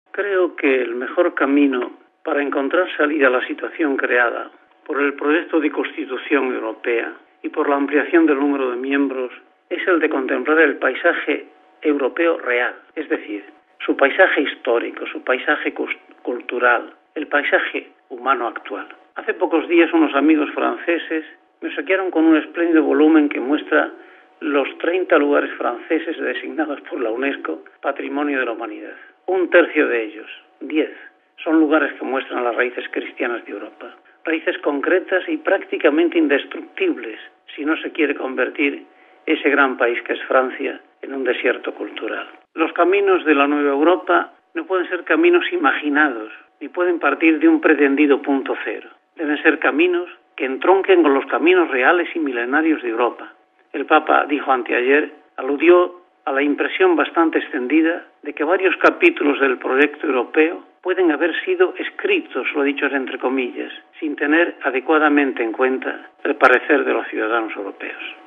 Entrevista al Presidente de la Pontificia Academia Eclesiástica, el arzobispo Justo Mullor García